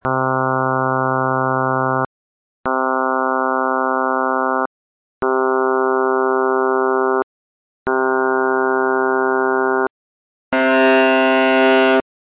second sound clip. You will hear five 2-second notes: 1) f0+f1+...+f9+f10 as before, 2) f1+...+f9+f10 -- the fundamental f0 has been silenced, 3) f1+3*f2+f3+...+f9+f10 -- fundamental absent and harmonic f2 three times greater than the others, 4) f1+3*f2+f3+...+f9+f10 with amplitudes made similar to those in the viola spectrum above, 5) the open C note on an actual viola.
When the fundamental at 131 Hz is silenced, we feel that the note has lost its bass fullness, but you may still agree that the note has the same pitch.
Seq131-missing-f0.mp3